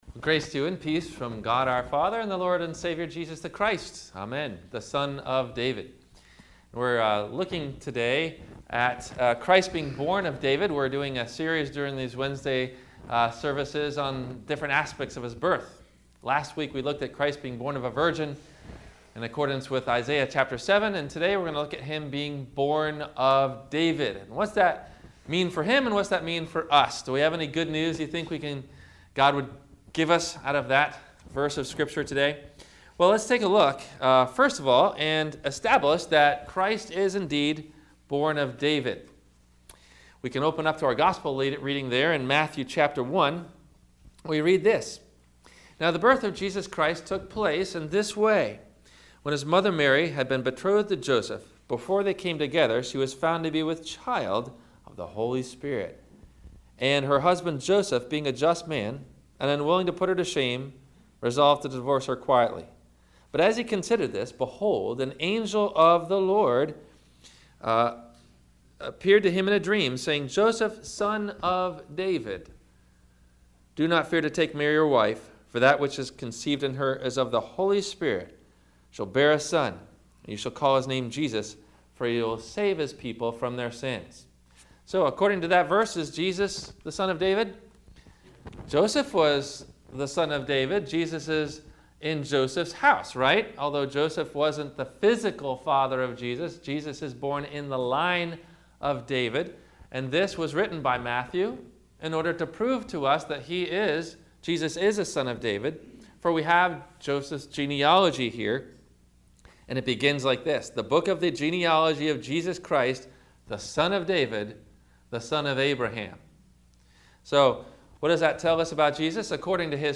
What’s The Significance about being Born of David? – Wednesday – Advent – Week 2 – Sermon – December 10 2014